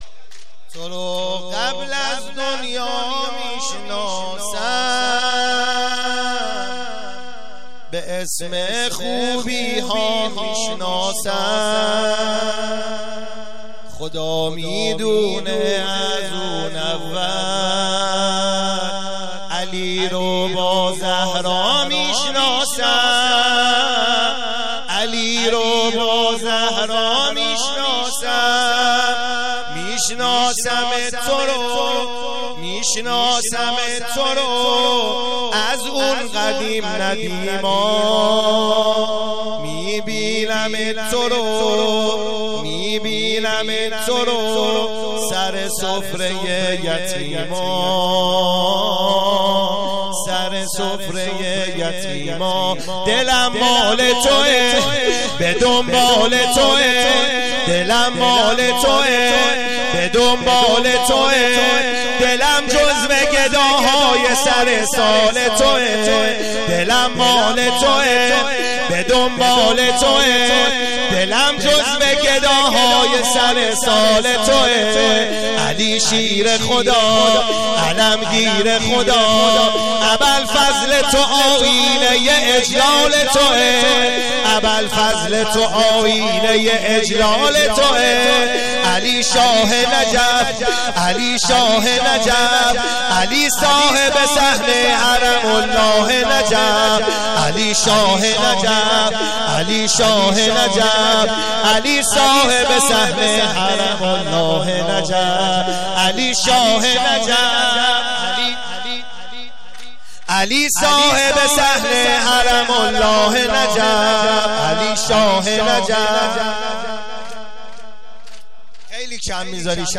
سرود عید غدیر خم